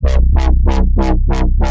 140 BPM循环 " FX白噪声
描述：Whitenoise dubstep声音效果
Tag: 回响贝斯 白噪声 隔音FX